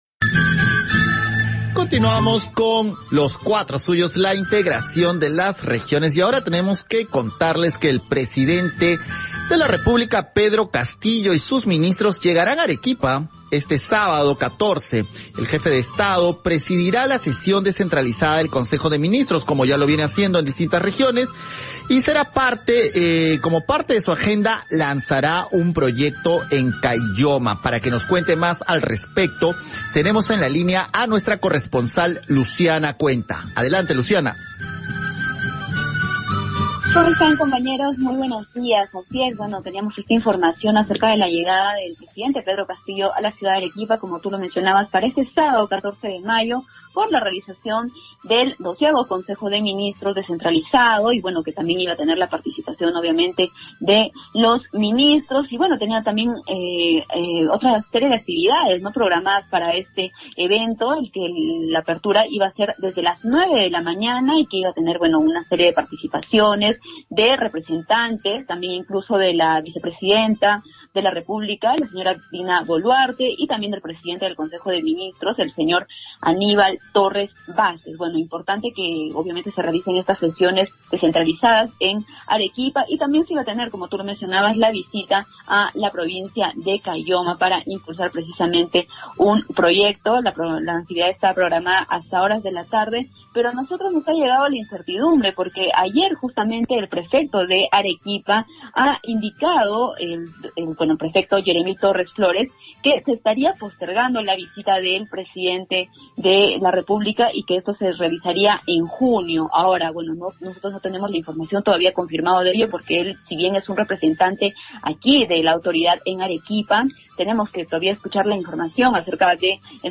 Reporte de Arequipa